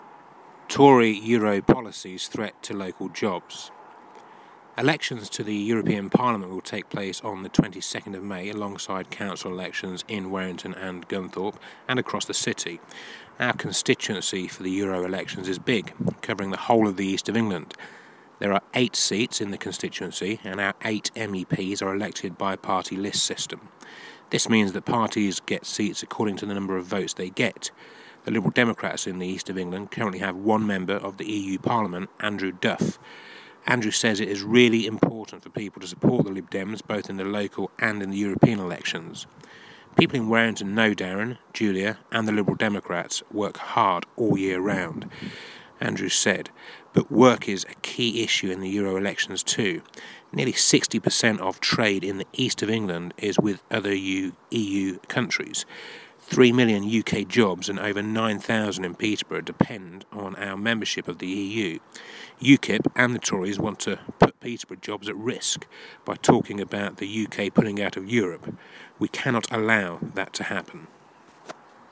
Cllr Darren Fower narrates the article about EU membership from the latest edition of the FOCUS newsletter!